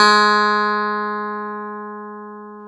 CLV_ClavDBG#4 2a.wav